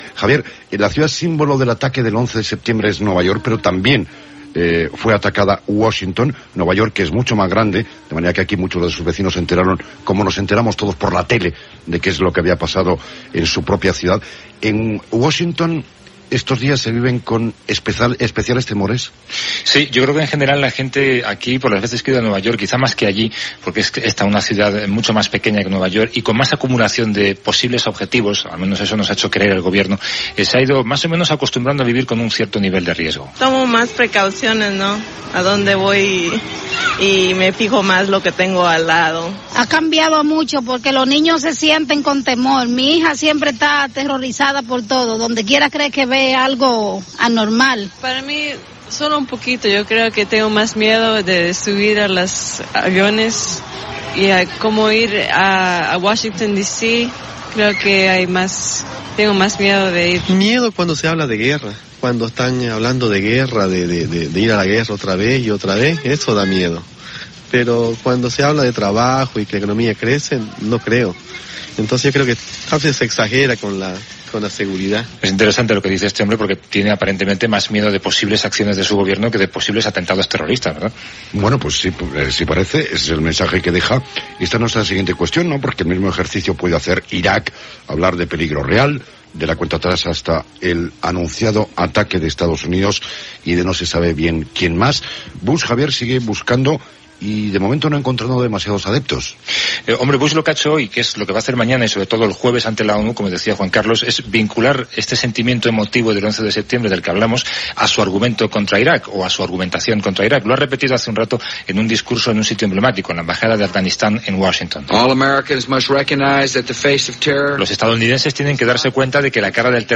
Programa fet des de Nova York un any després de l'atemptat que va destruir les torres bessones de Manhattan.
Informatiu